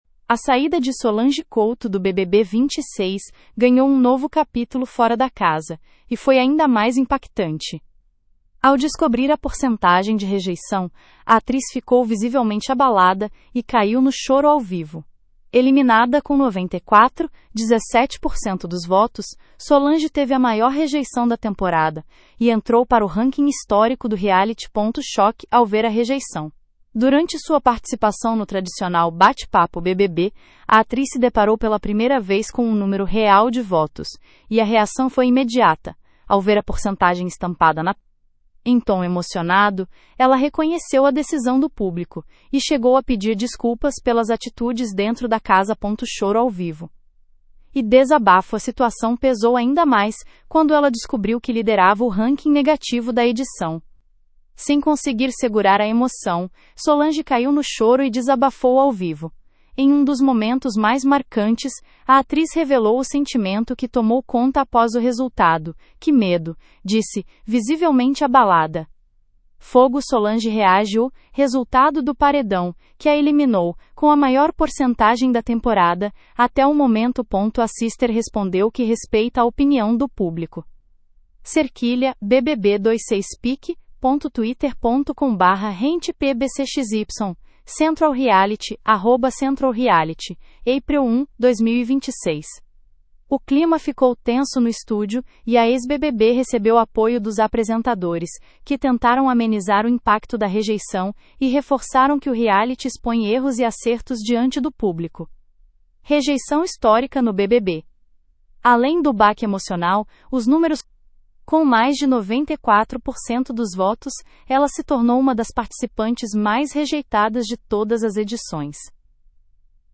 Em tom emocionado, ela reconheceu a decisão do público e chegou a pedir desculpas pelas atitudes dentro da casa.
Sem conseguir segurar a emoção, Solange caiu no choro e desabafou ao vivo.
O clima ficou tenso no estúdio, e a ex-BBB recebeu apoio dos apresentadores, que tentaram amenizar o impacto da rejeição e reforçaram que o reality expõe erros e acertos diante do público.